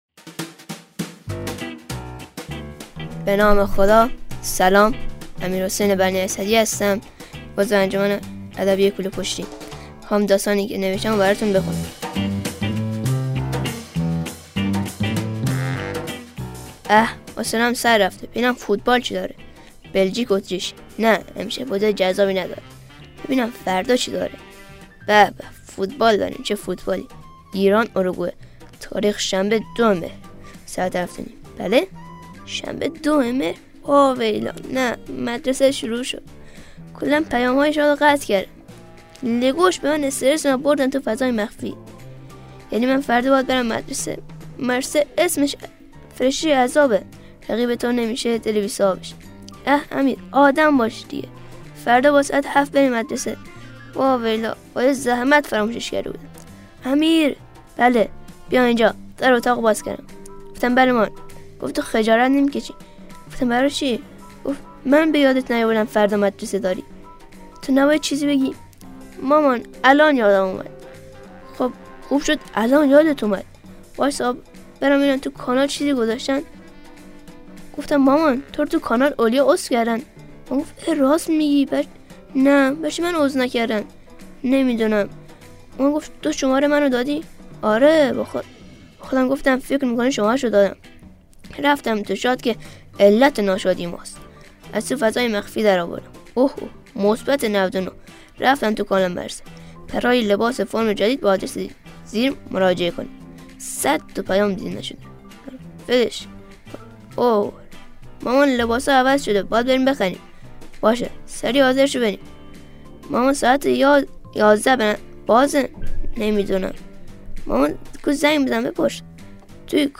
داستان طنز